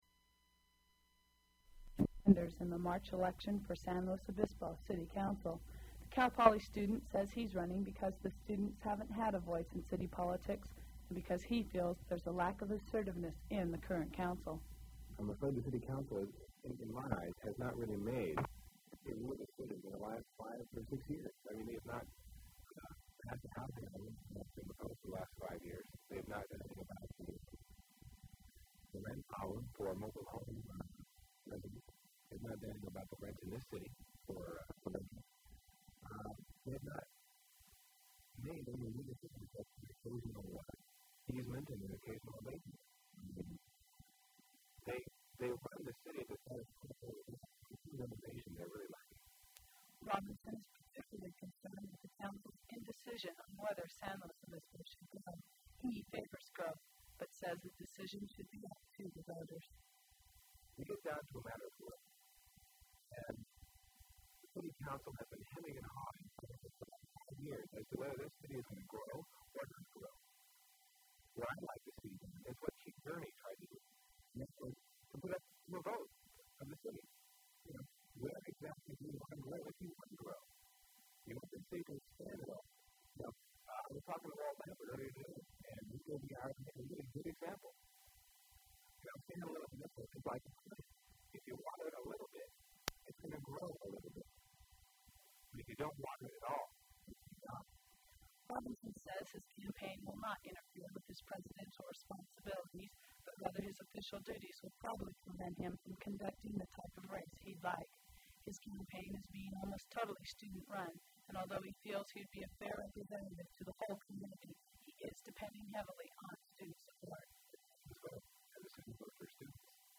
Cataract awareness commercial
Public service announcement about crime awareness and prevention
Non Smoking Public Service Advertisement